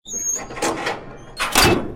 TrapdoorSkrip.ogg